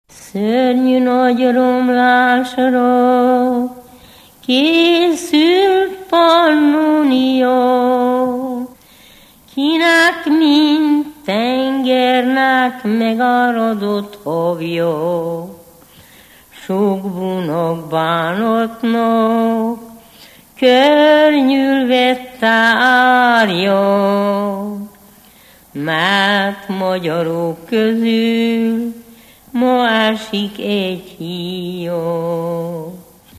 Alföld - Torontál vm. - Hertelendyfalva
ének
Műfaj: Históriás ének
Stílus: 4. Sirató stílusú dallamok
Kadencia: 5 (1) b3 1